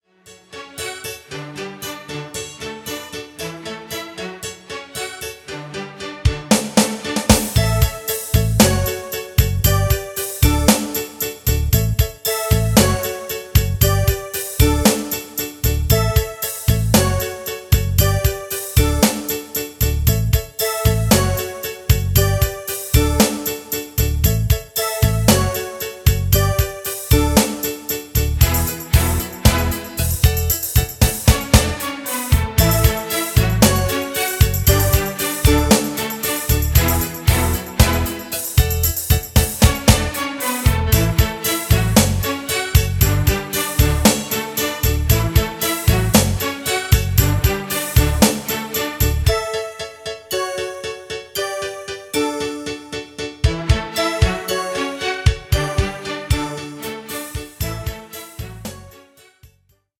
Das Playback-Album zur gleichnamigen Produktion.